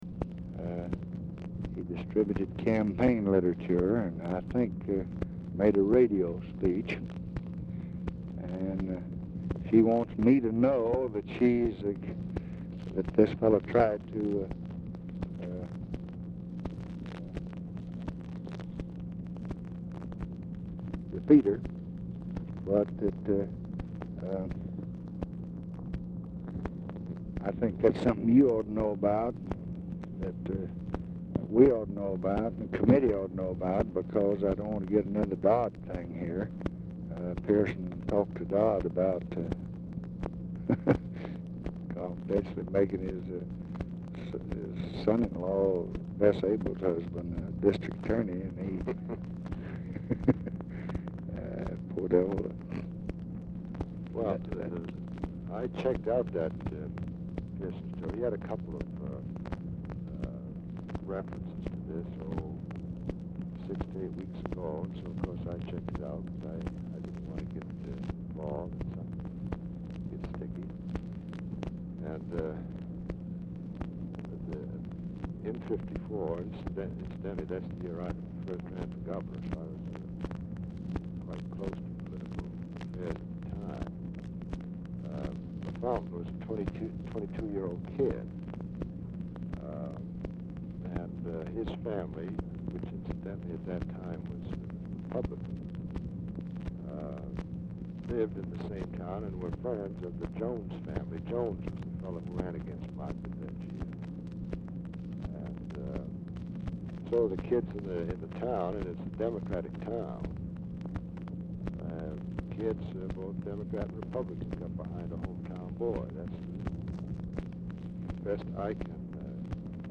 RECORDING STARTS AFTER CONVERSATION HAS BEGUN; MUSKIE IS DIFFICULT TO HEAR
Telephone conversation
Dictation belt